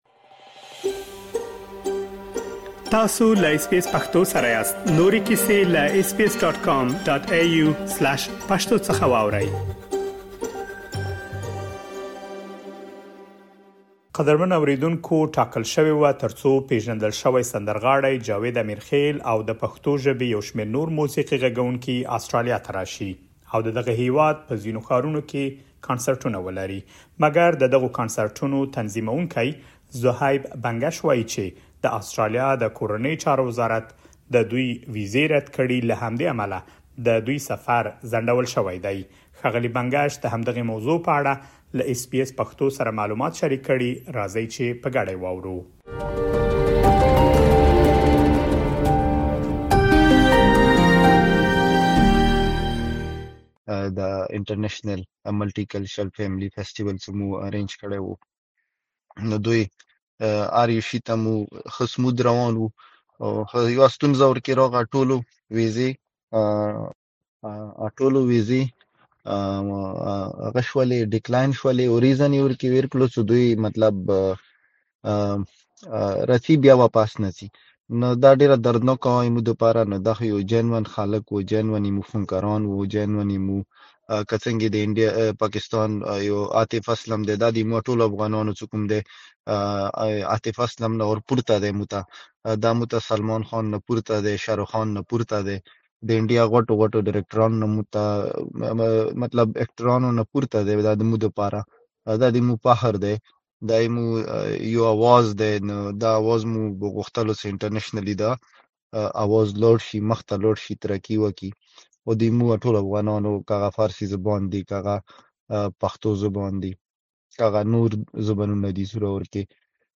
تاسو کولی شئ لا ډېر معلومات په غږیز پیغام کې واورئ.